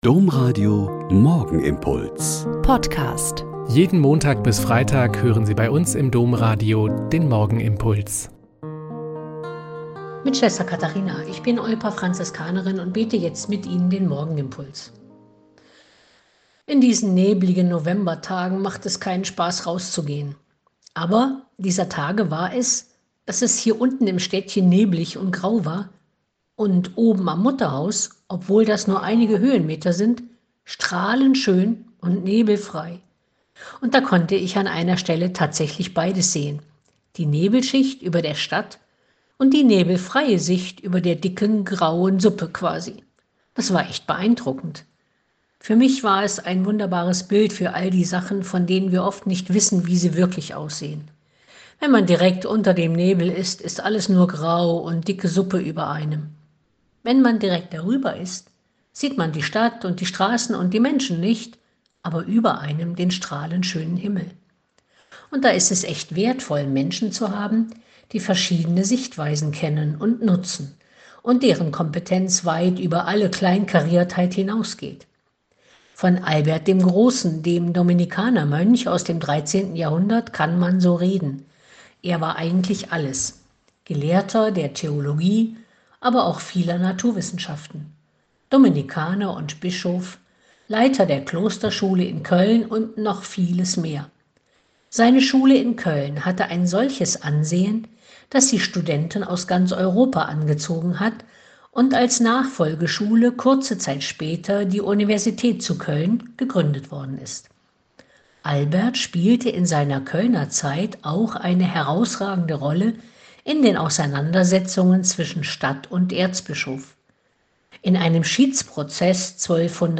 Lk 17,26-37 - Gespräch